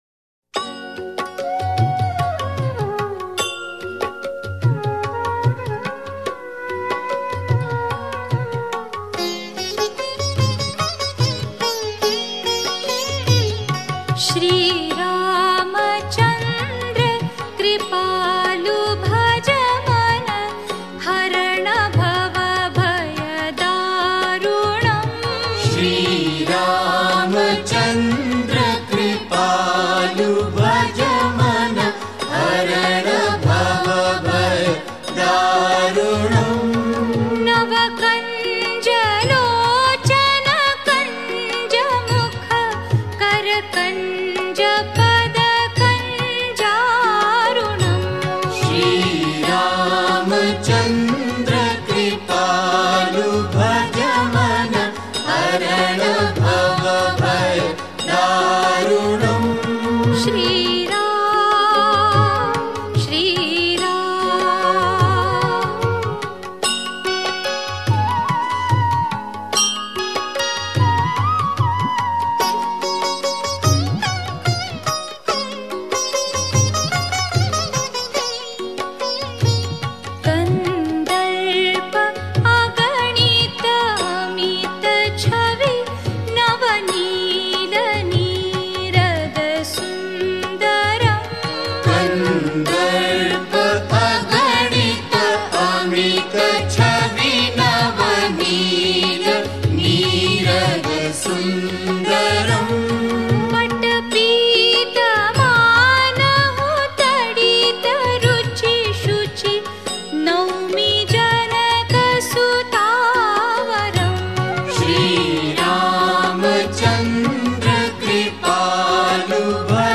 Devotional Songs > Shree Ram Bhajans